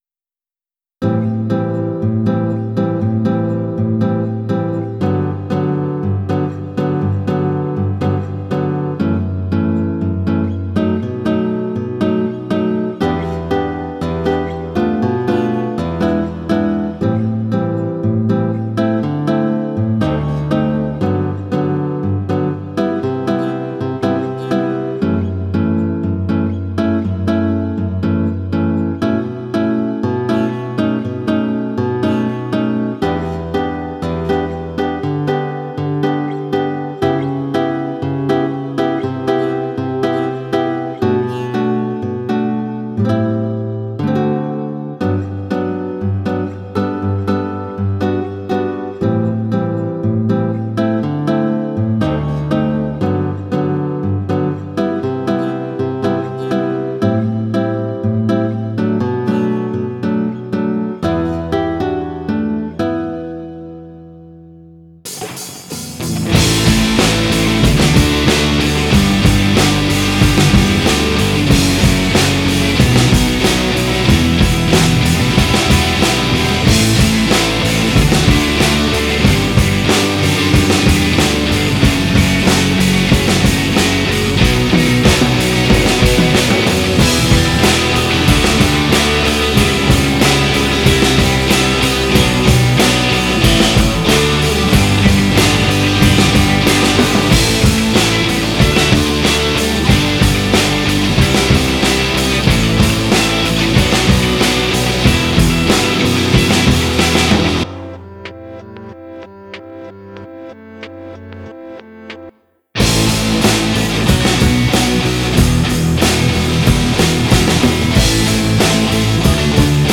声を合成する楽器として、パラメータ（GEN その他）の変更で、こういった声を合成させることも可能です。
※ これらのデモソングは、歌唱表現としてベロシティ、ダイナミクス、ブレシネスなど、様々な調整を行っています。